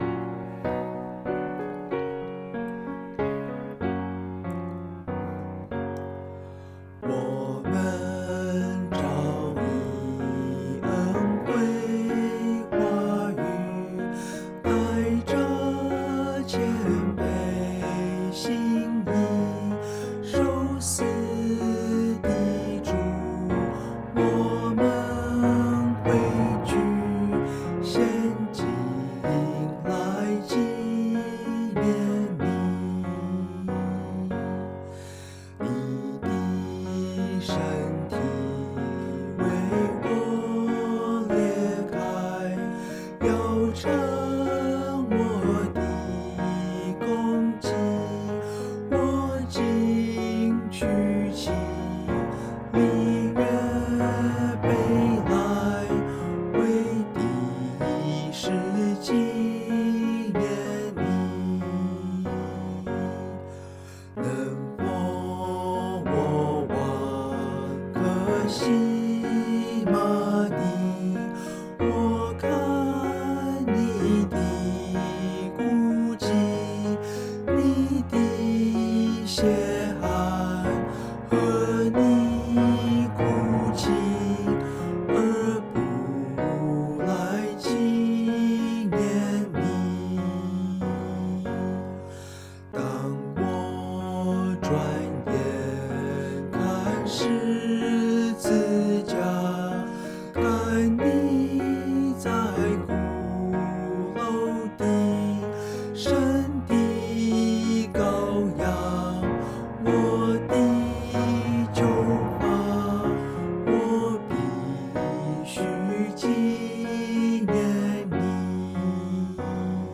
調號： G大調 節奏： 3/4
ch_0173_vocal.mp3